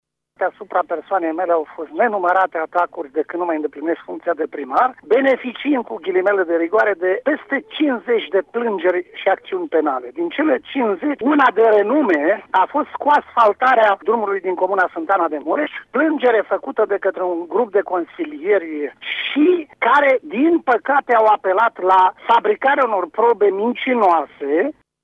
Fostul primar al comunei Sântana, Viorel Bubău, a declarat, pentru RTM, că este vorba de o plângere penală făcută de un grup de consilieri locali în legătură cu asfaltarea drumului din Sântana: